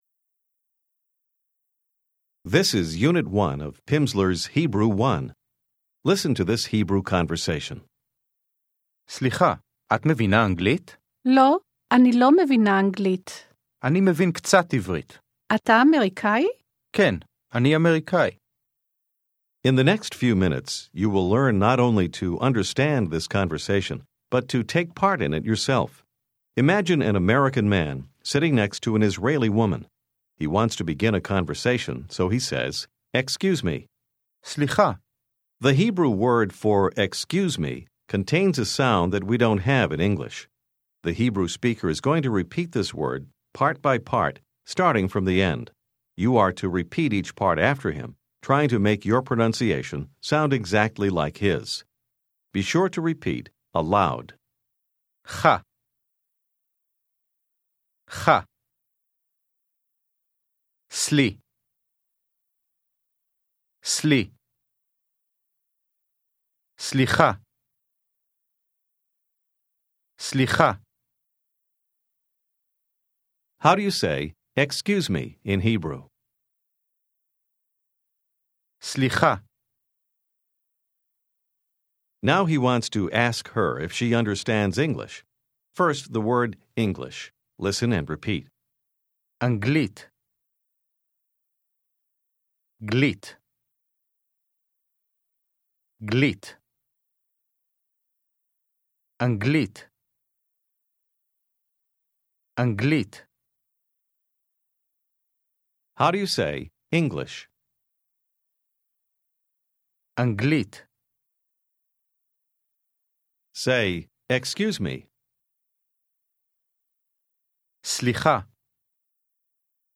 Hebrew Phase 1, Unit 1 contains 30 minutes of spoken language practice, with an introductory conversation, and isolated vocabulary and structures.